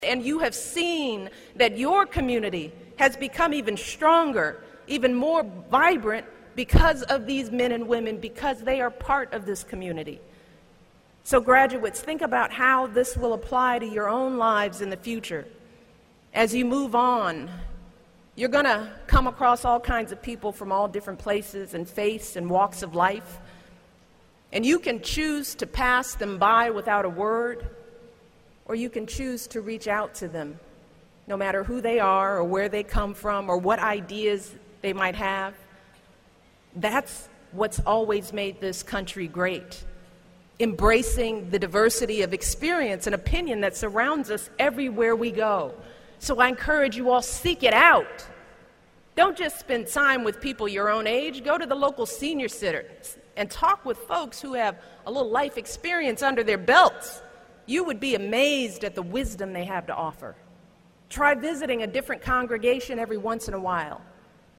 公众人物毕业演讲第273期:米歇尔2013东肯塔基大学14 听力文件下载—在线英语听力室